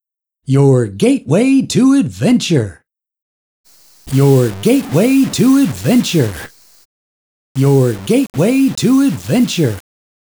Quantize-noise is close, (and doesn’t require the overhead of re-sampling to 1MHz) …